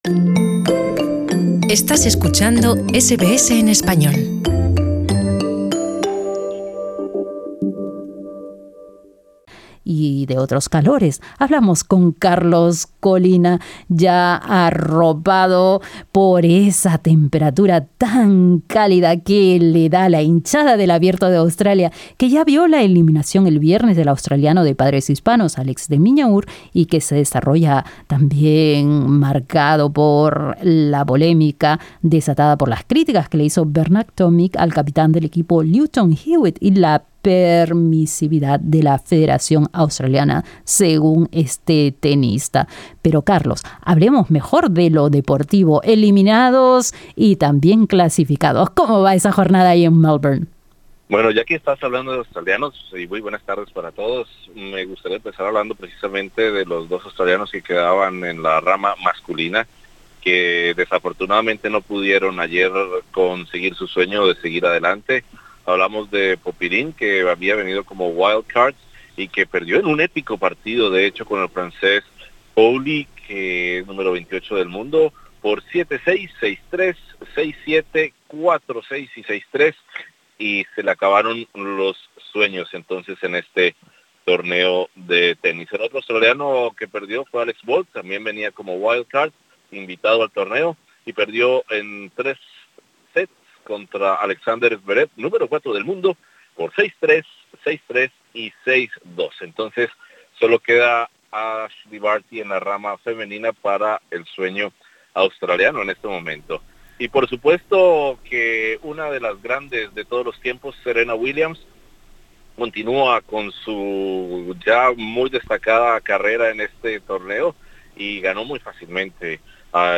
informe